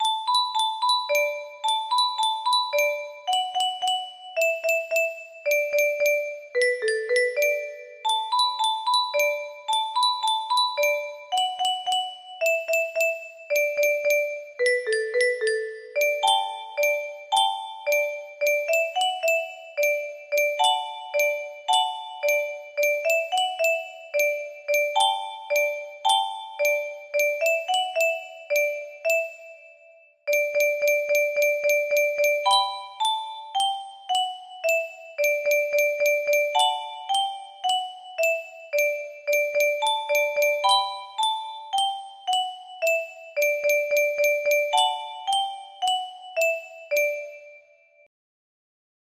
Rose Song music box melody